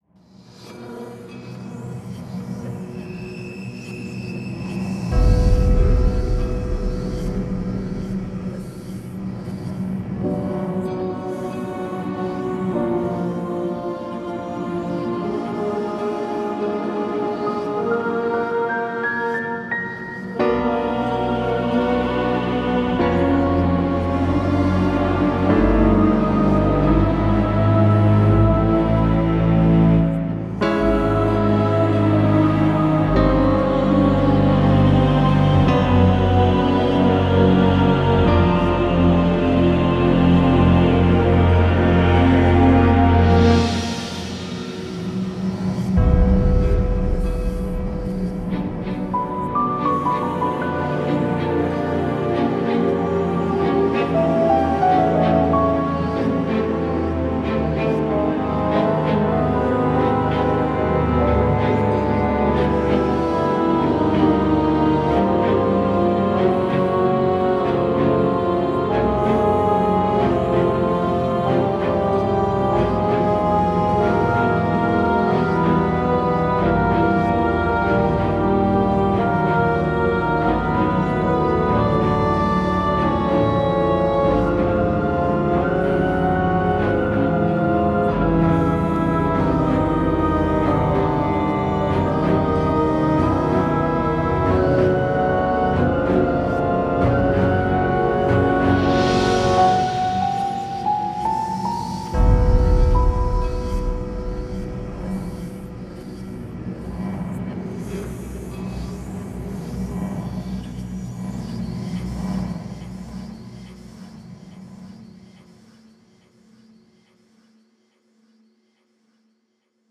Music at Disciples 2 style (spoiler at title 😋)